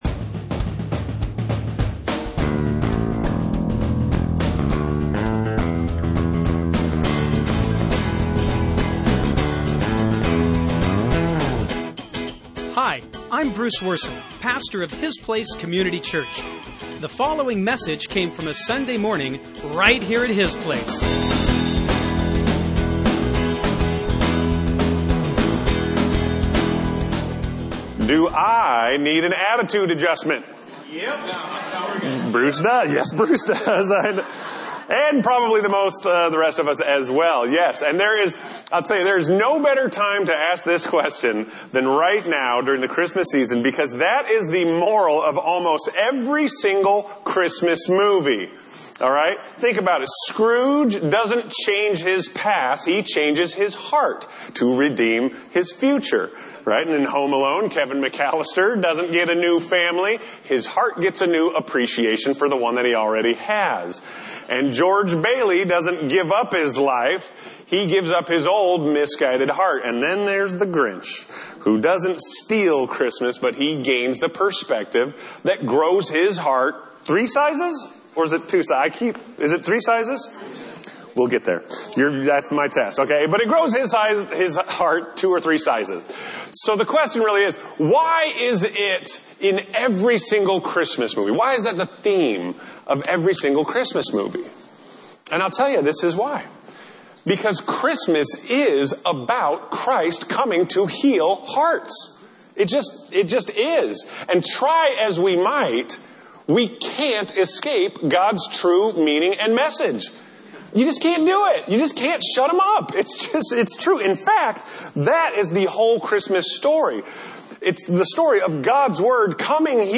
Sunday morning messages from His Place Community Church in Burlington, Washington. These surprisingly candid teachings incorporate a balanced mix of lighthearted self-awareness and thoughtful God-awareness.